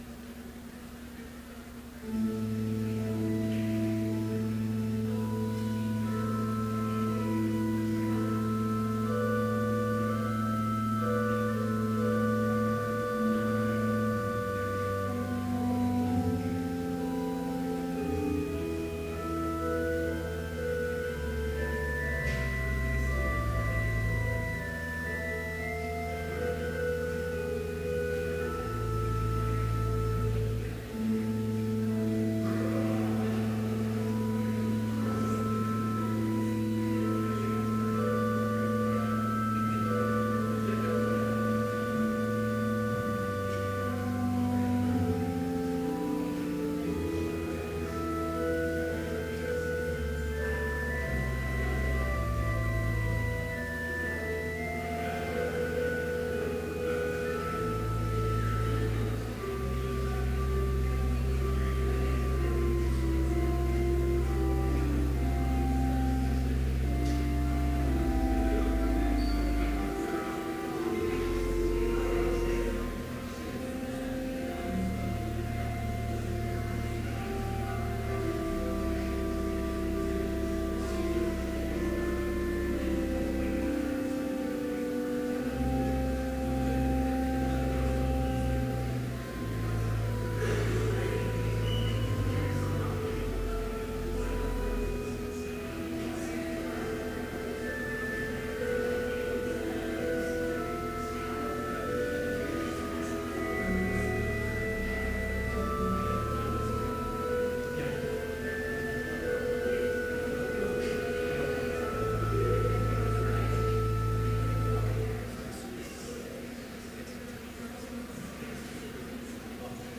Complete service audio for Chapel - April 16, 2014
Sermon Only
Order of Service Prelude Hymn 324, vv. 1, 4, 12, 13 & 14, O Jesus, at Your Altar Now Reading: I Corinthians 11:26-29 Homily Prayer Hymn 327, vv. 2 & 3, Thy holy body… Benediction Postlude